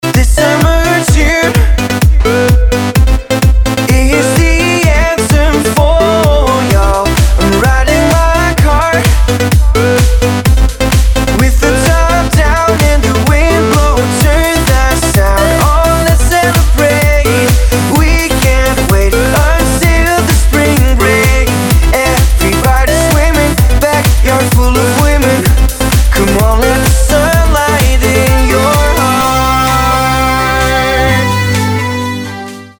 Švajčiarsky DJ a produncet